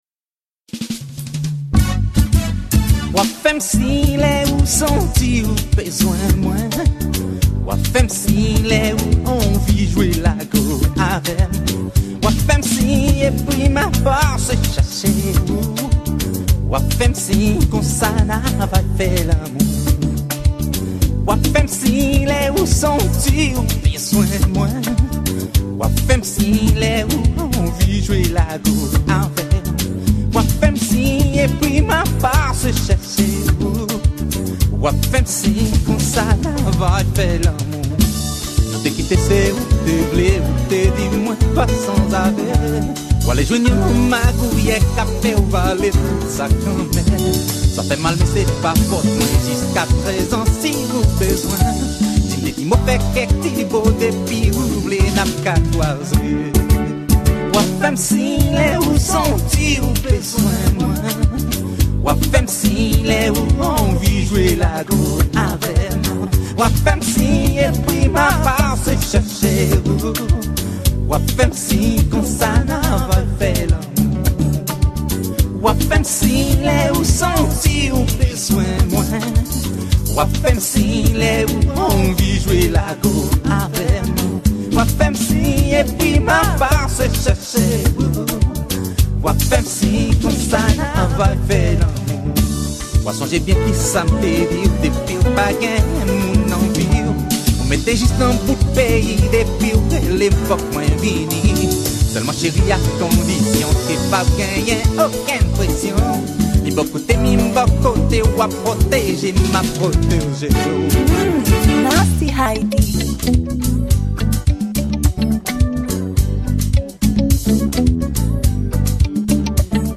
Genre : konpa